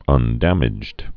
(ŭn-dămĭjd)